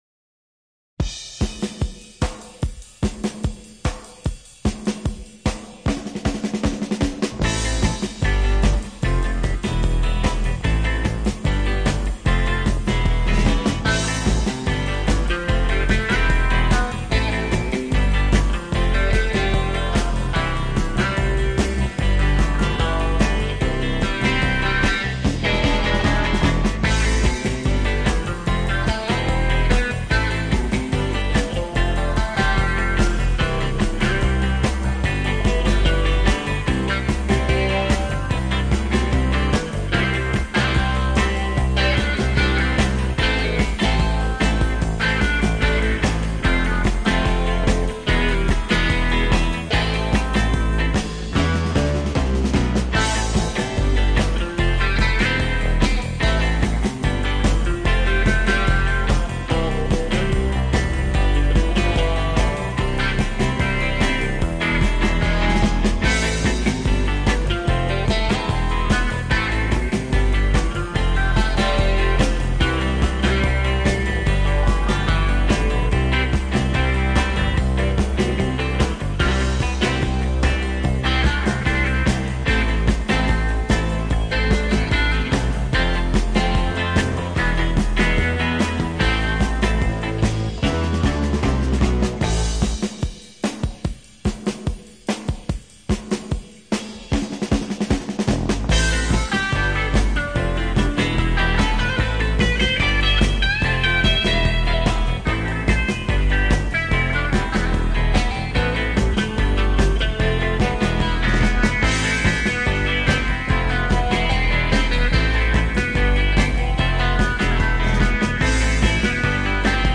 Жанр: Surf, Instrumental Rock